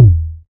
edm-perc-14.wav